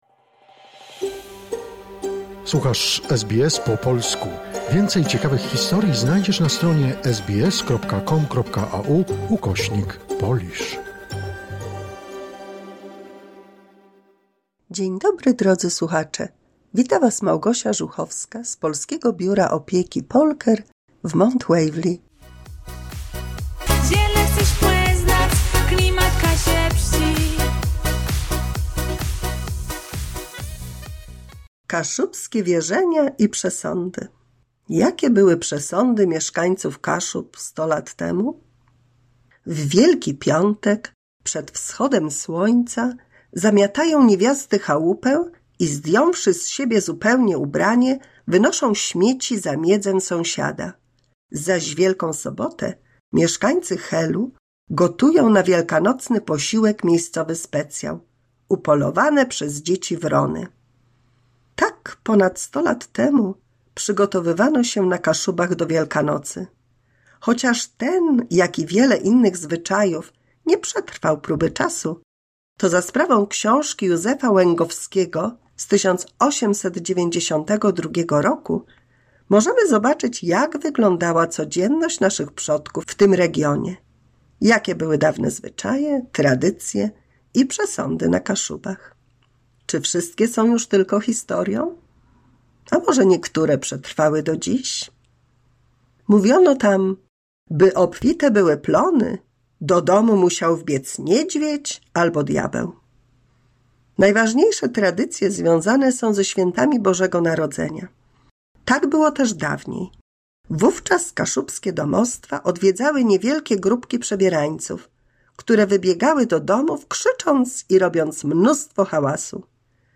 mini słuchowisko dla polskich seniorów